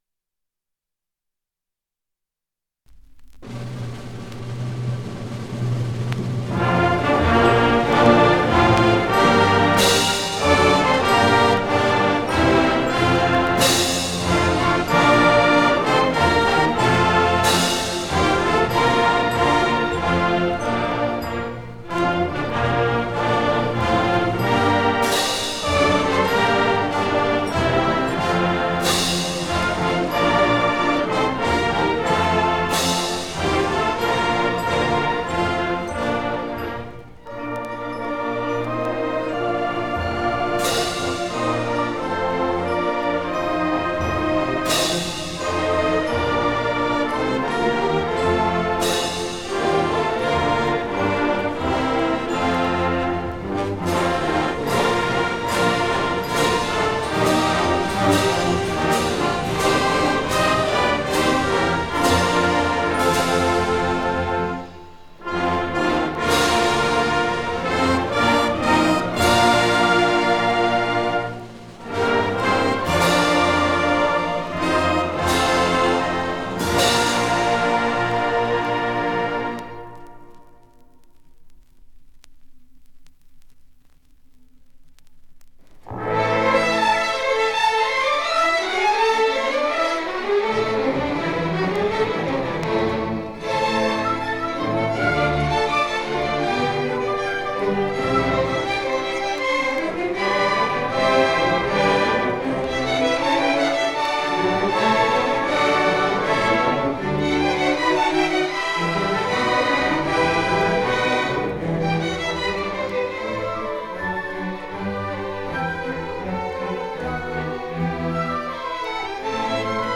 dbb215de21babbf81fac8d8c7749aba2059b2023.mp3 Title 1970 Music in May orchestra and band performance recording Description An audio recording of the 1970 Music in May orchestra and band performance at Pacific University.
It brings outstanding high school music students together on the university campus for several days of lessons and events, culminating in the final concert that this recording preserves.